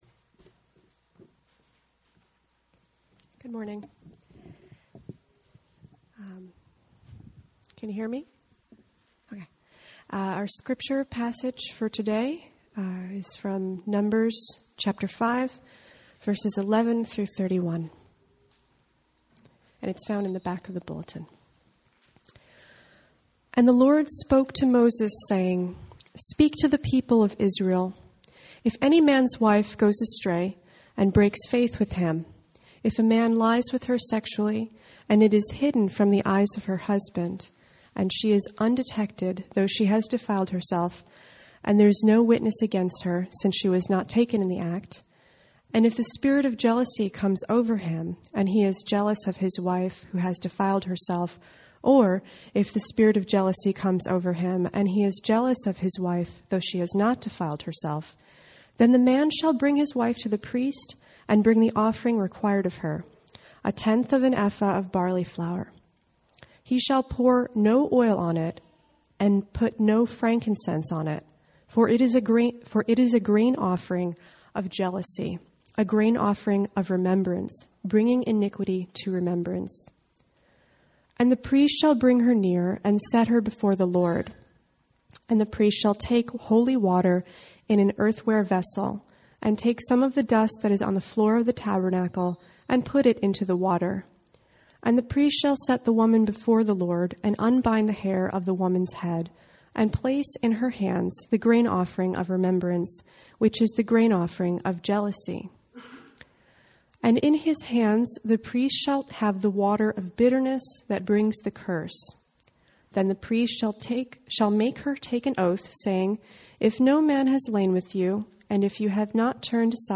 –Proverbs 27:4 I open this sermon asking the congregation what bothers them about the passage just read. The passage is Numbers 5:11-31 , addressing when an Israelite husband was jealous about his wife.